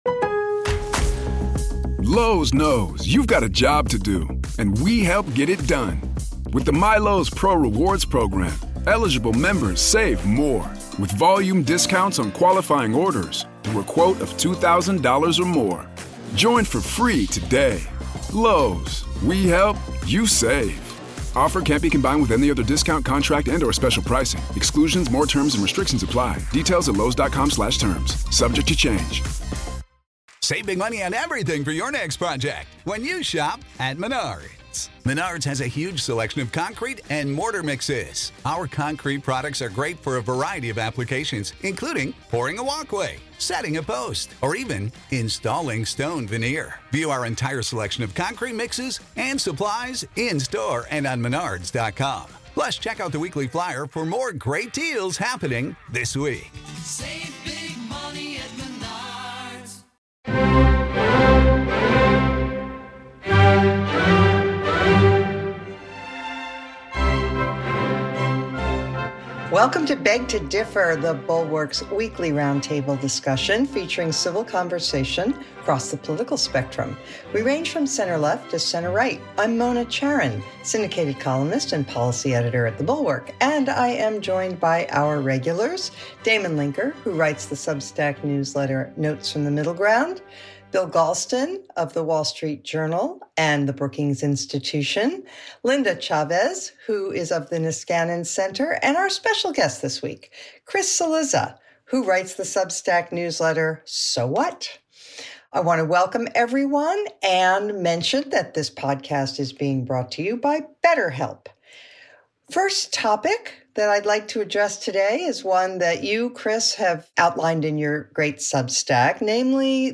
Chris Cillizza joins the panel for a thought experiment on Biden.